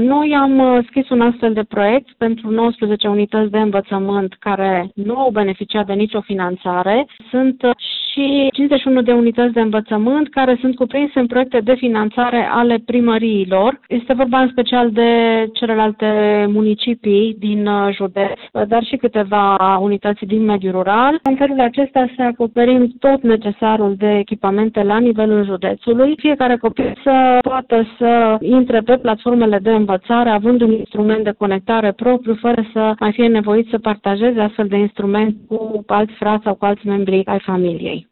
Inspectoratul Școlar și mai multe primării din județ vor accesa în perioada următoare și bani europeni destinați achiziției de tablete și alte echipamente IT, spune inspectorul școlar general Marinela Marc, pe care o puteți asculta aici: